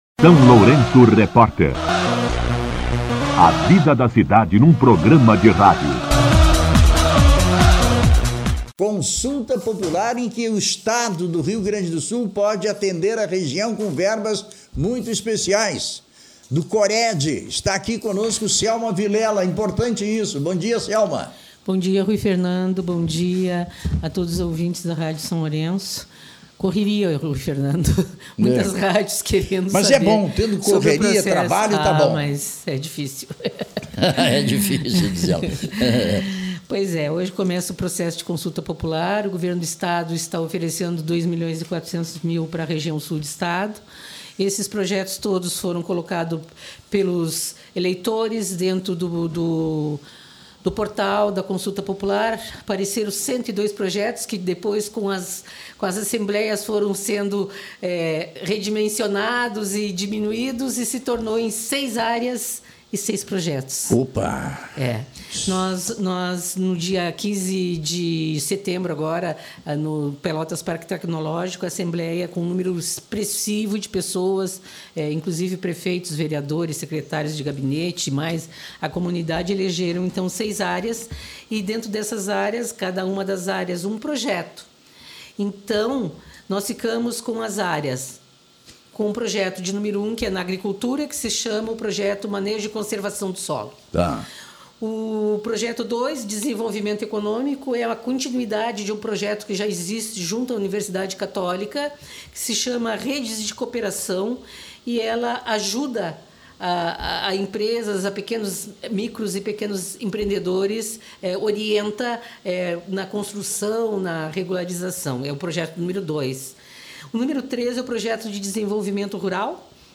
concedeu entrevista ao SLR RÁDIO na segunda-feira